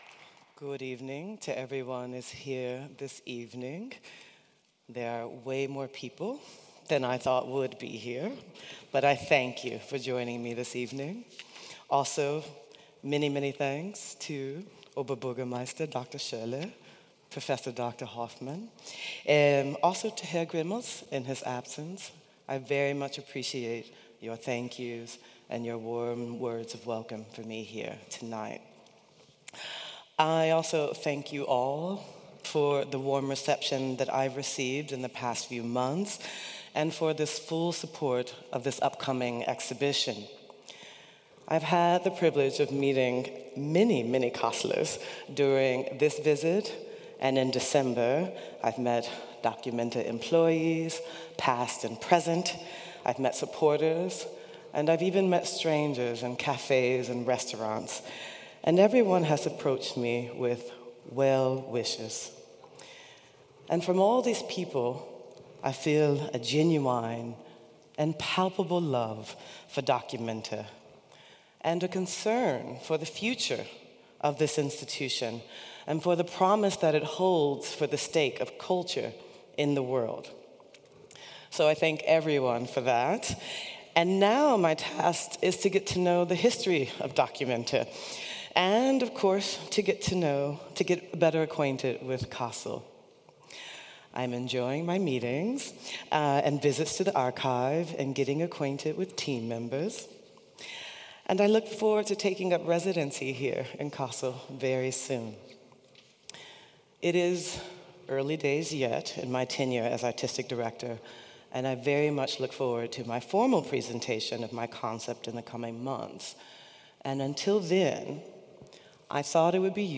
Wie positioniert sich die kommende documenta in einer Welt voller Brüche und Bewegung? In dieser SprechZeit-Folge dokumentieren wir Naomi Beckwiths öffentliche Rede, mit der sich die Kuratorin der documenta 16 am 18. März 2025 in der documenta-Halle der interessierten Öffentlichkeit sowie der nationalen und internationalen Presse vorstellte. Ein Abend, der Fragen aufwirft, Haltungen zeigt und erste Gedanken zur Ausstellung im Jahr 2027 vermittelt.